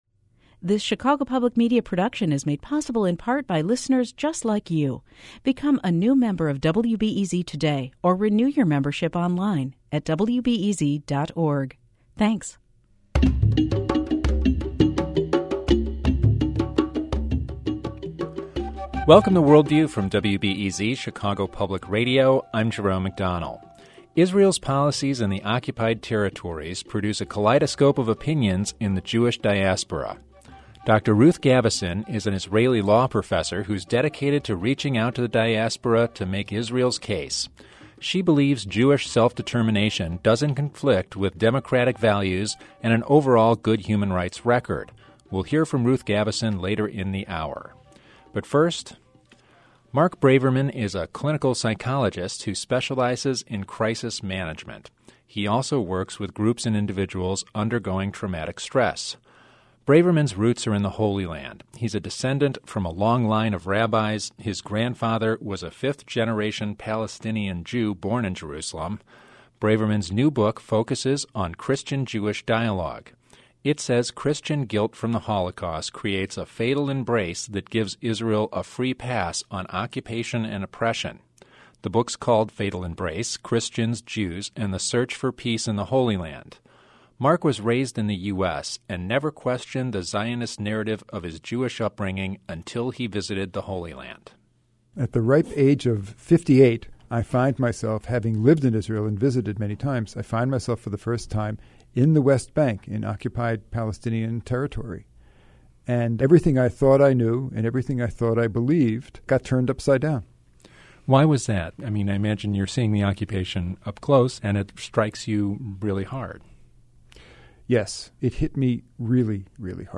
It is recommended to hear the full interview if you have the time, else the first 6 to 7 minutes are enough. You will hear for yourself how the Palestinian point of view has been articulated by an Israeli: